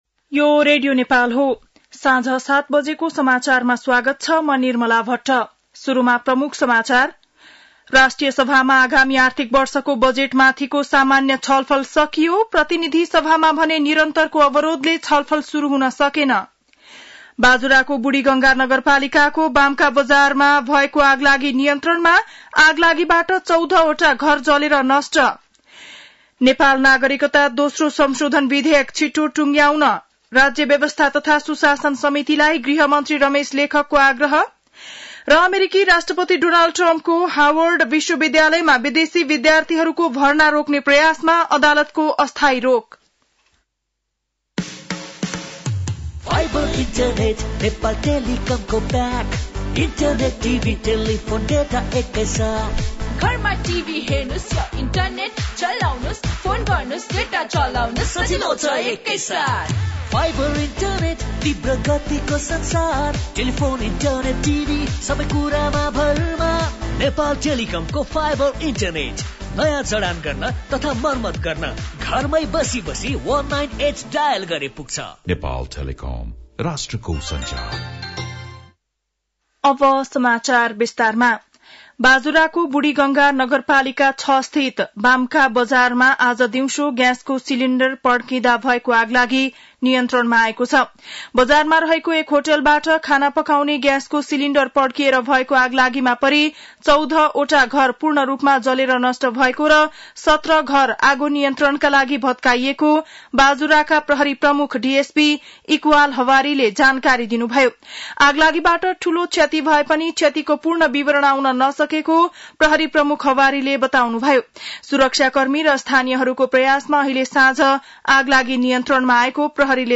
7-pm-nepali-news-2-23.mp3